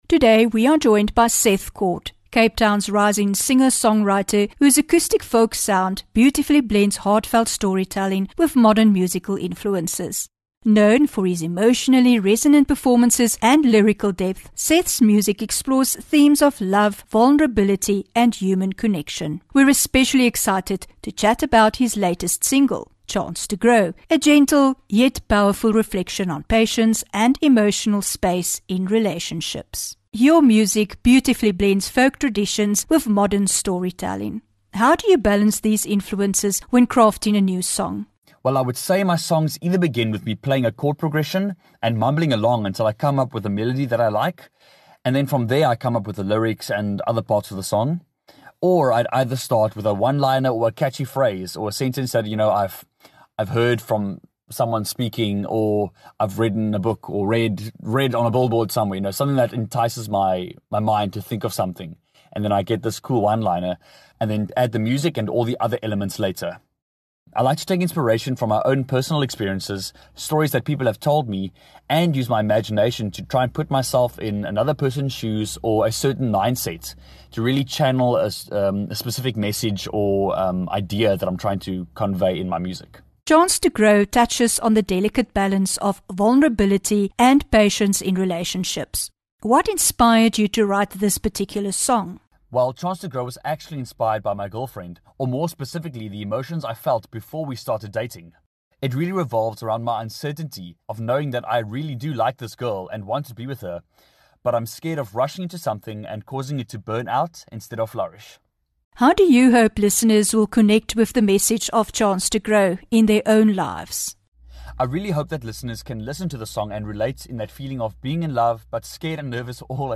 9 Jul INTERVIEW